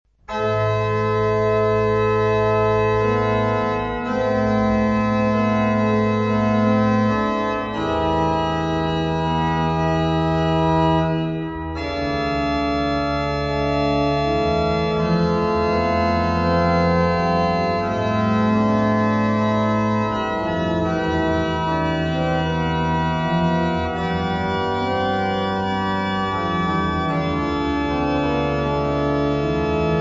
Personaggi e interpreti: organo ; Leonhardt, Gustav